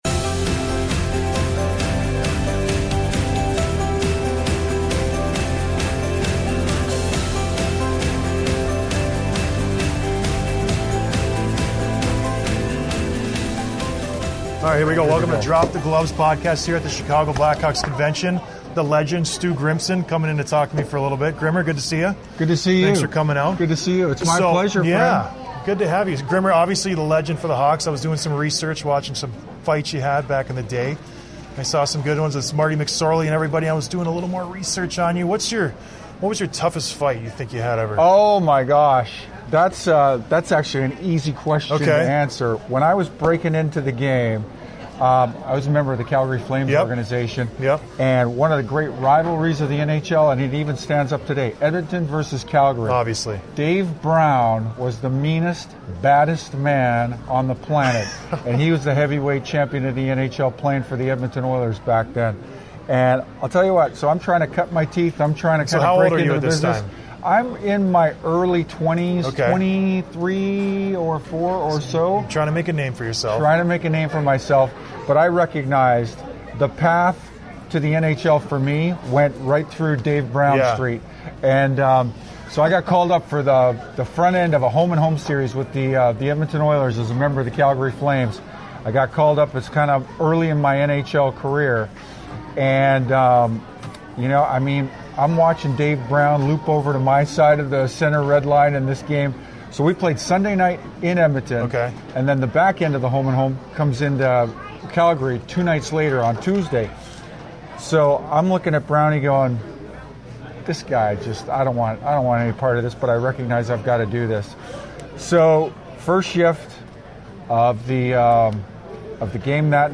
We're back at the Chicago Blackhawks Convention this week with the NHL Legend , Stu Grimson. It was a great time catching up with Stu to talk a little about the Blackhawks and how fighting has changed throughout the years. We also get into what it is like going into your first ever fight after being called up to the NHL.